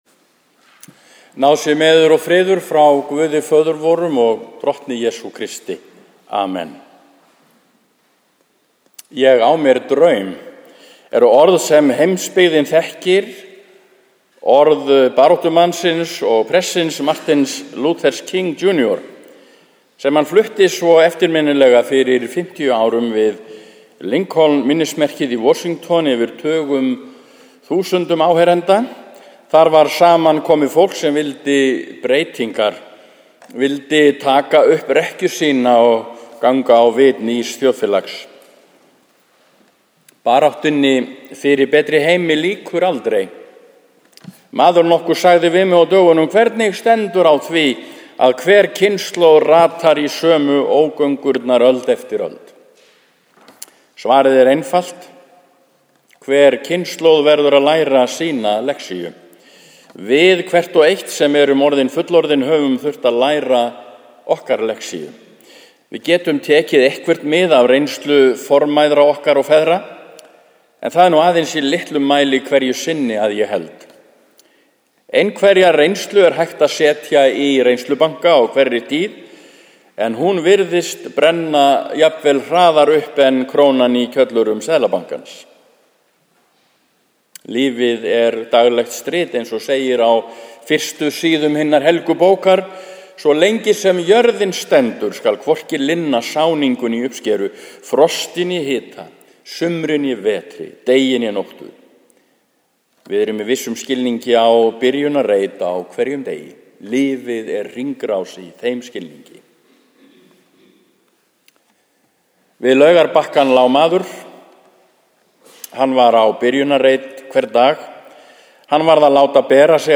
Draumurinn Prédikun í Neskirkju sunnudaginn 1. september 2013 – 14. sd. e. trin.
Einhver innskot eru á hljóðupptökunni sem ekki eru í textanum.